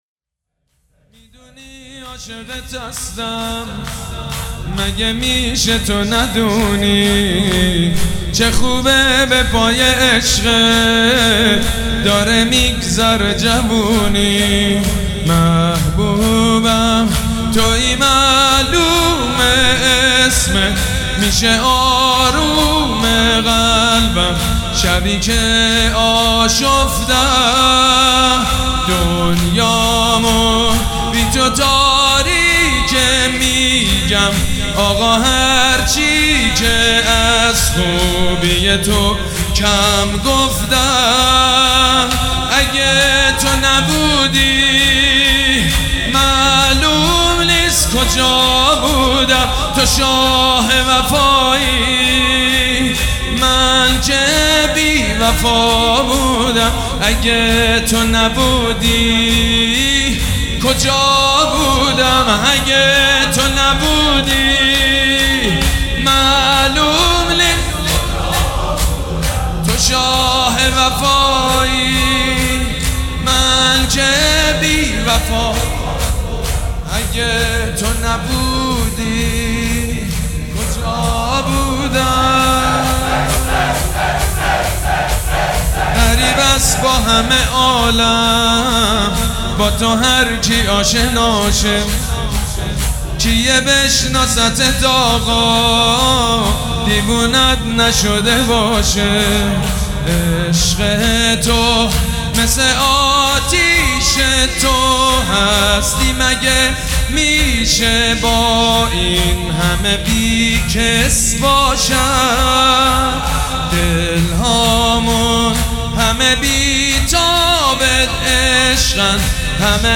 سبک اثــر شور
مداح حاج سید مجید بنی فاطمه
مراسم عزاداری شب چهارم